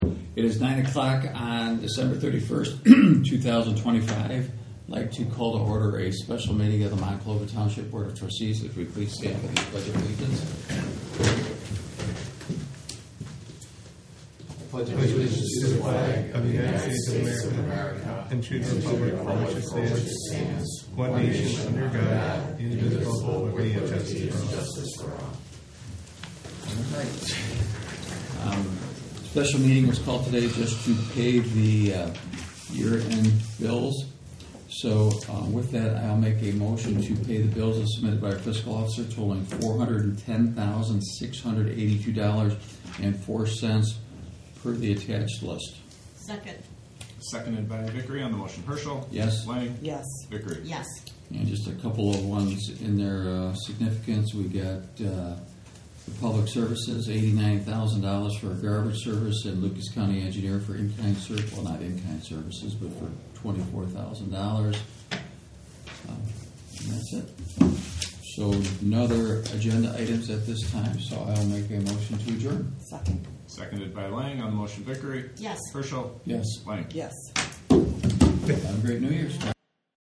General Session Audio
Year-End Mtg